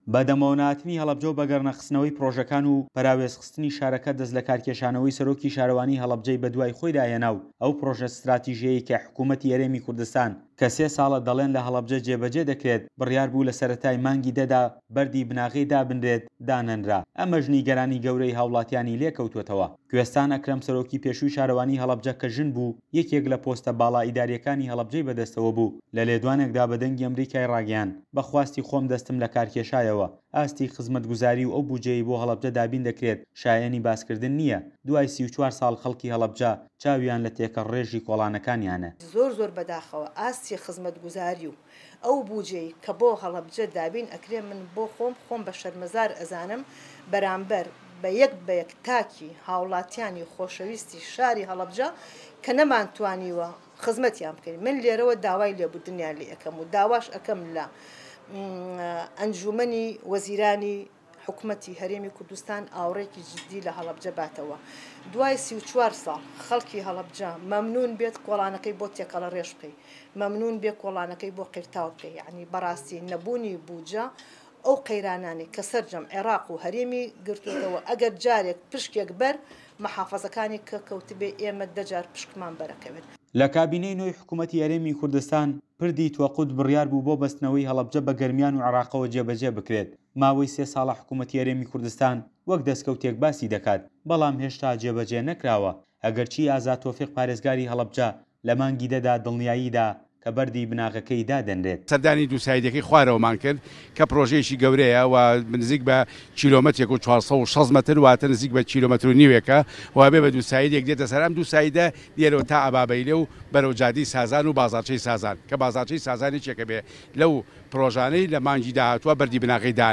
ڕاپۆرتی پەیامنێر